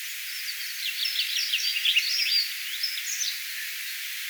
rautiaisen säkeen lopussa pajusirkun ääni?
rautiaisen_sae_onko_lopussa_pajusirkun_aani.mp3